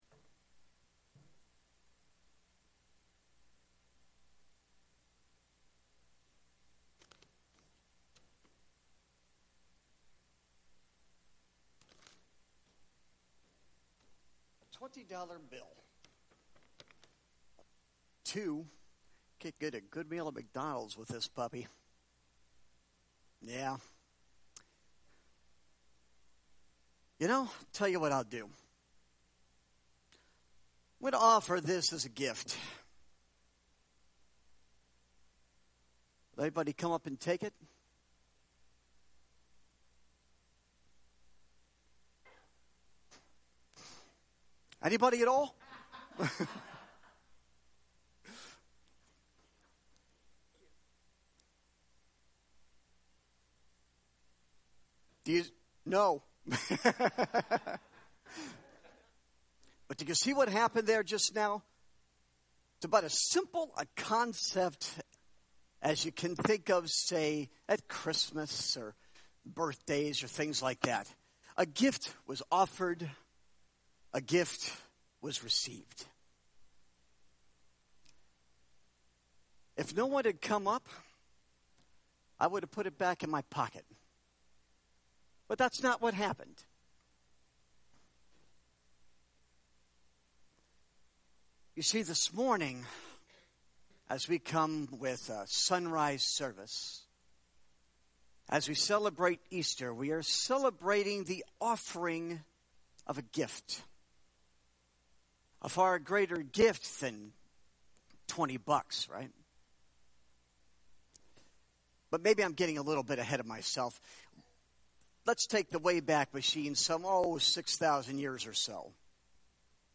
Sonrise service devotional